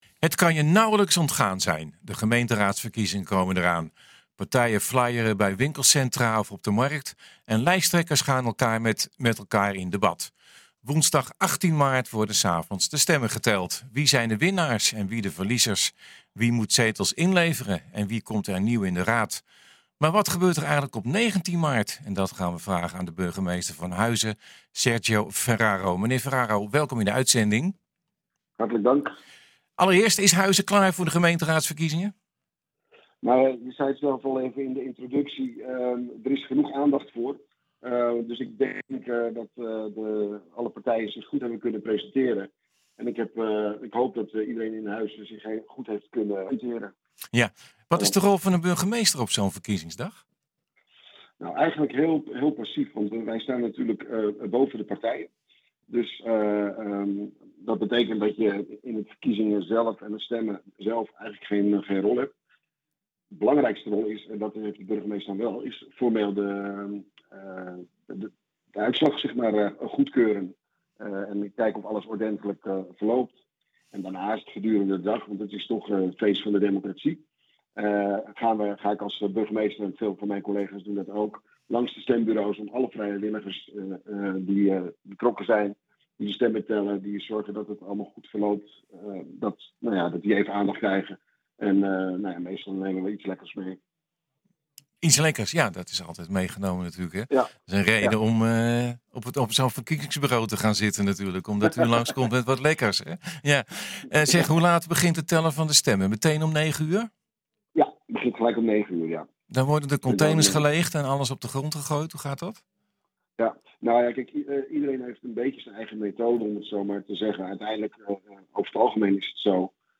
Maar wat gebeurt er op 19 maart. Dat vragen we aan de burgemeester van Huizen, Serge Ferraro.